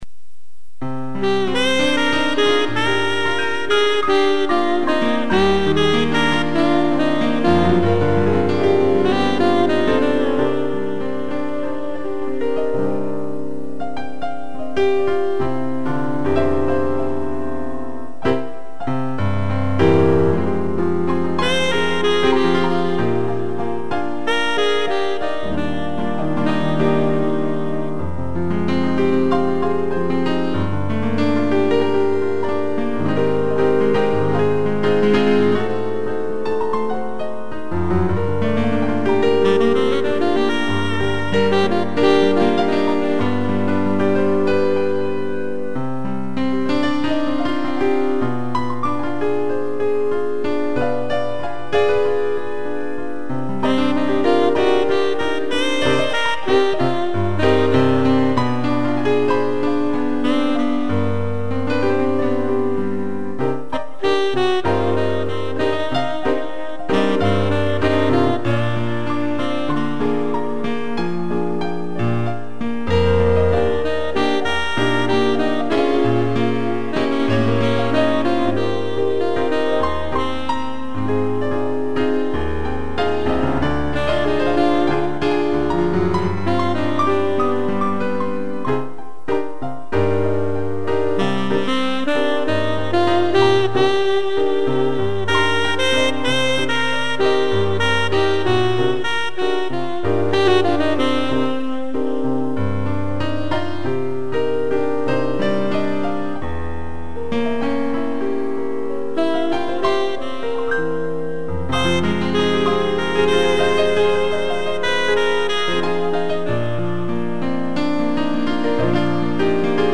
2 pianos e sax
(instrumental)